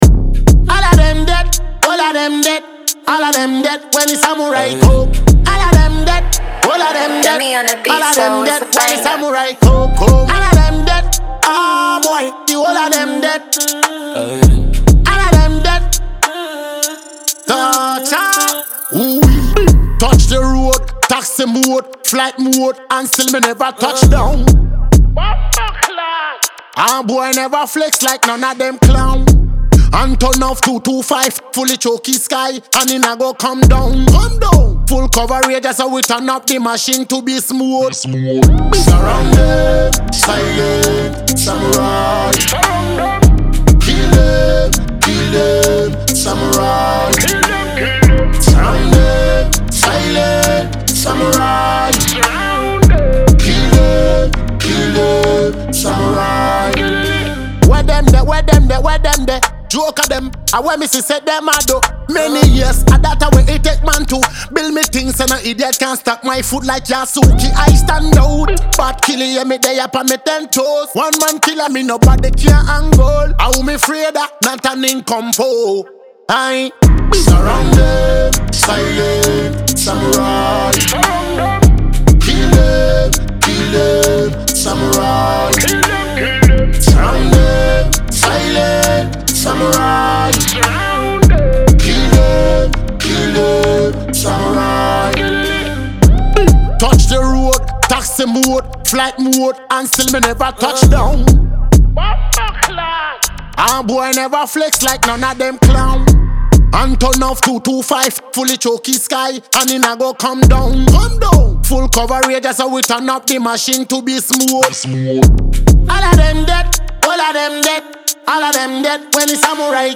a Ghanaian dancehall and multiple award-winning act